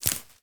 Minecraft Version Minecraft Version 25w18a Latest Release | Latest Snapshot 25w18a / assets / minecraft / sounds / block / cactus_flower / break2.ogg Compare With Compare With Latest Release | Latest Snapshot